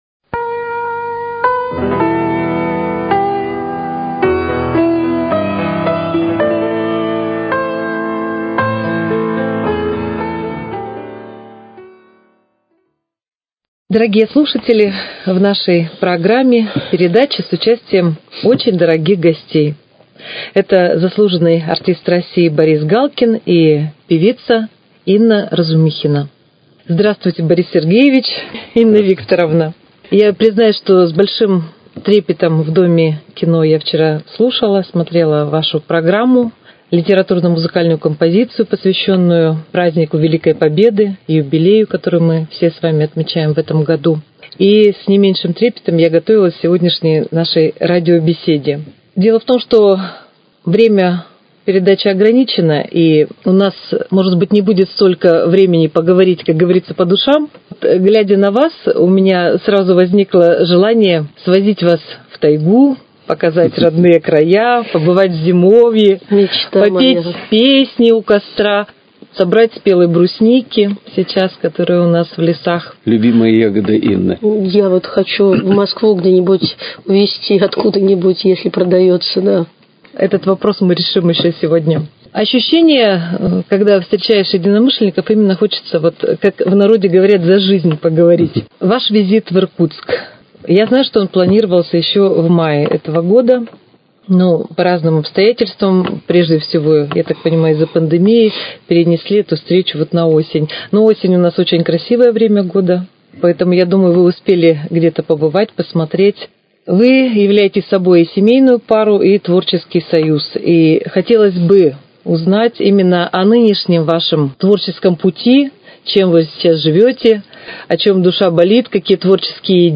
Музыкальный салон: Беседа с заслуженным артистом РФ Борисом Галкиным и певицей